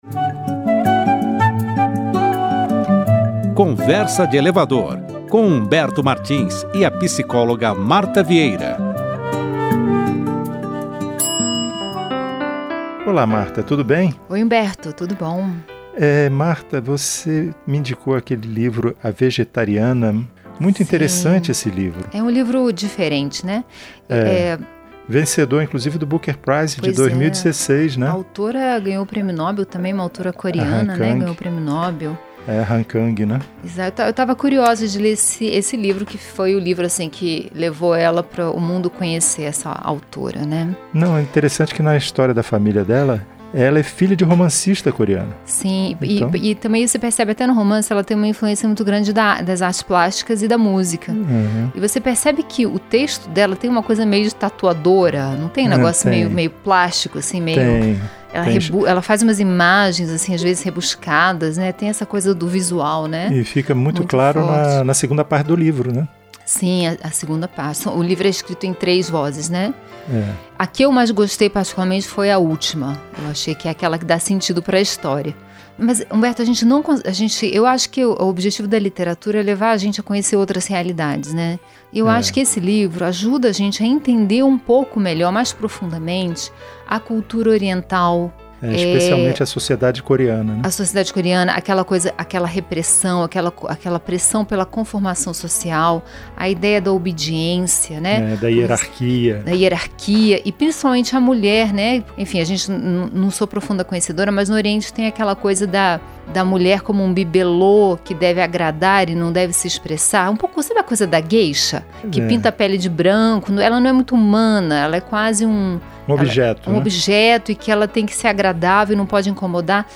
O Conversa de Elevador é um programa de bate-papo sobre temas variados, e que pretende ser ao mesmo tempo leve, breve e divertido, sem deixar de provocar uma reflexão no ouvinte.
Enfim, é uma conversa solta e sem compromisso, marcada pelas experiências do dia-a-dia e pela convivência em um mundo que às vezes parece tão rápido e tão cheio de subidas e descidas quanto um elevador.